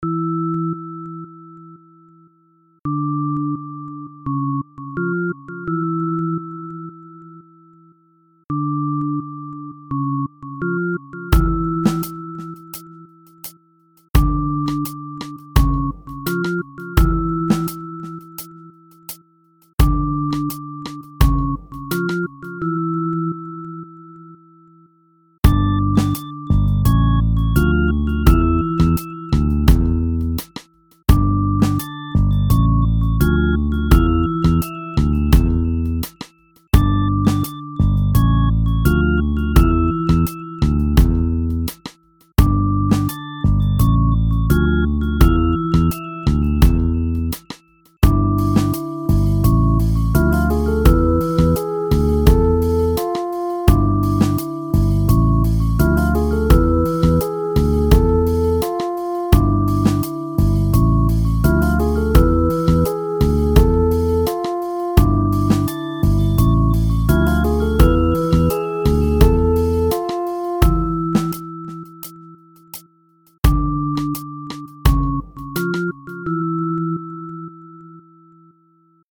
(композиція відображає приємний психоз тотального недосипання)